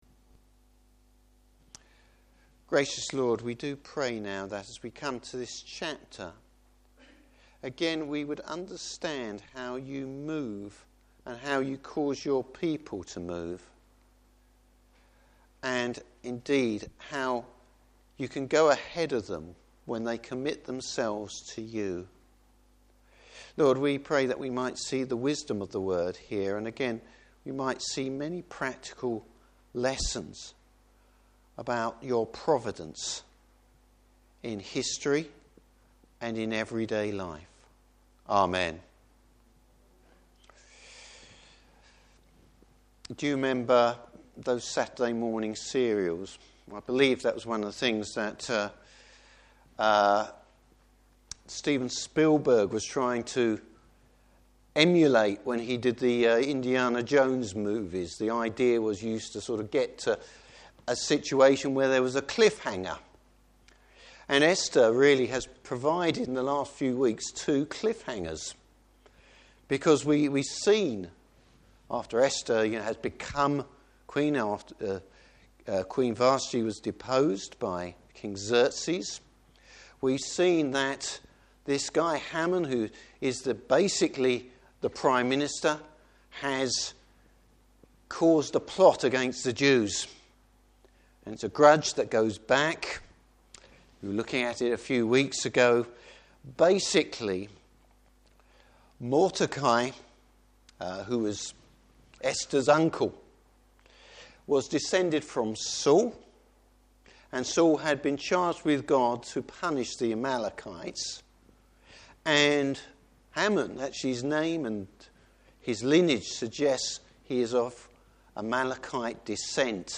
Service Type: Evening Service Wisdom in the face of confrontation.